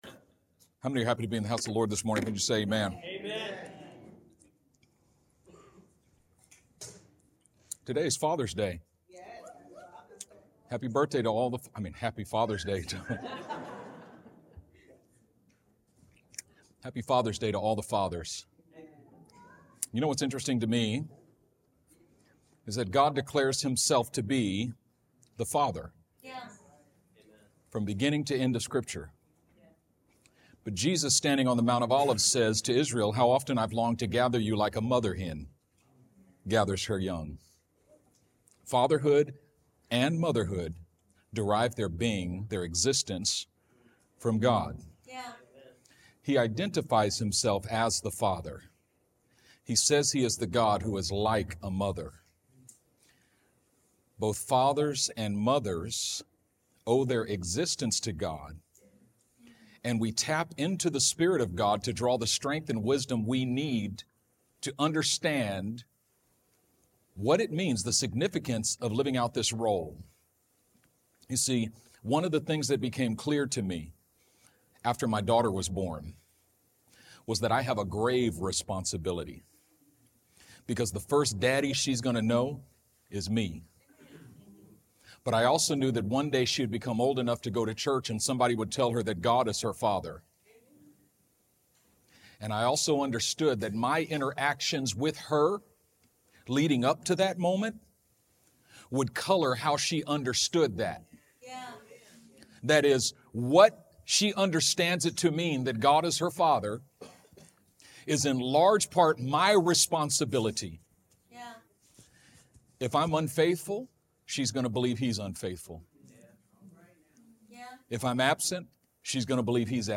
Sermon preached on June 15th.